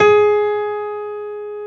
55p-pno23-G#3.wav